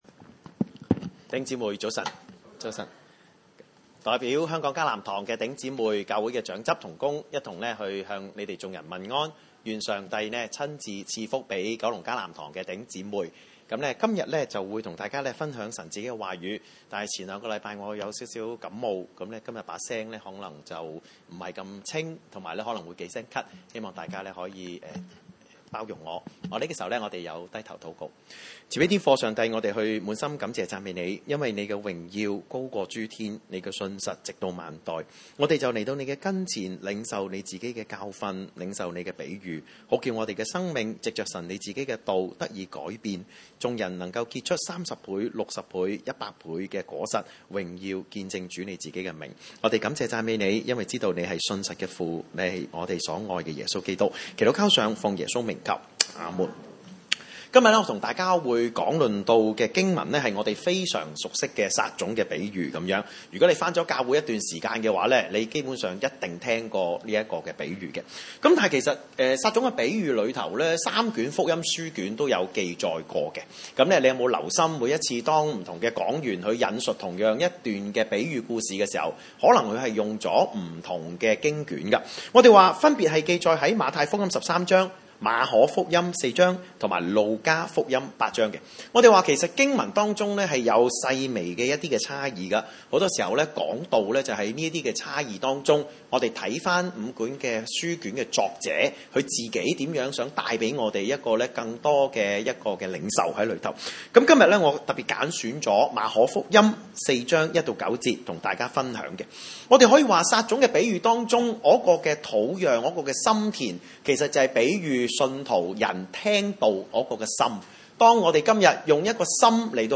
1-9 崇拜類別: 主日午堂崇拜 1 耶 穌 又 在 海 邊 教 訓 人 ． 有 許 多 人 到 他 那 裡 聚 集 他 只 得 上 船 坐 下、船 在 海 裡 、 眾 人 都 靠 近 海 站 在 岸 上 。